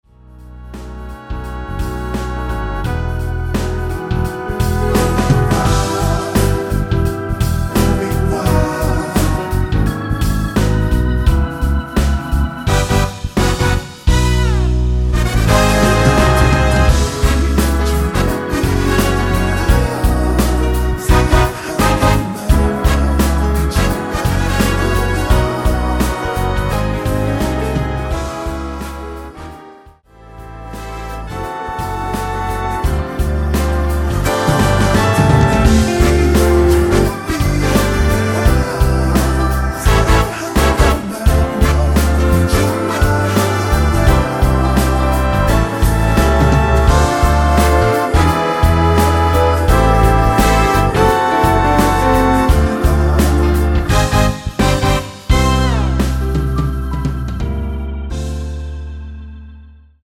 2분56초 부터 10초 정도 보컬 더블링 된 부분은 없습니다.(원키 코러스 MR 전제 미리듣기 가능)
원키에서(-2)내린 코러스 포함된 MR입니다.
앞부분30초, 뒷부분30초씩 편집해서 올려 드리고 있습니다.